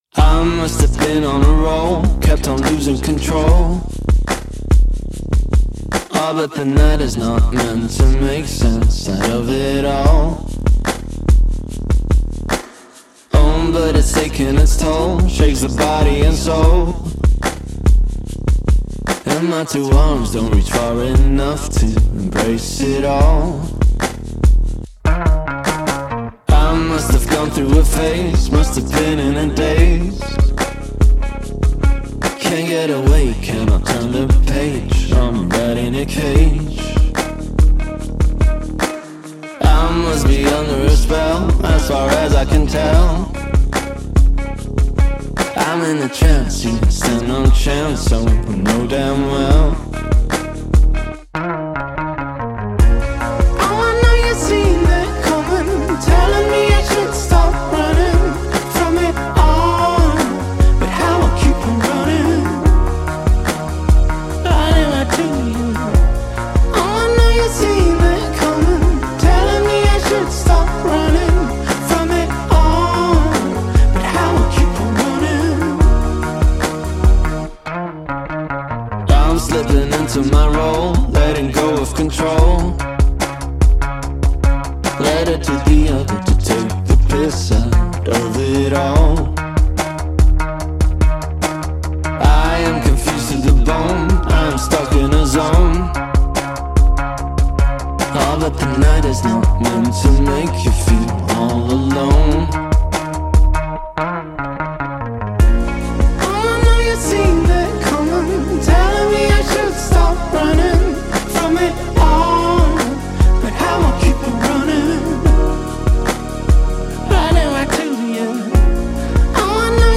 Rock راک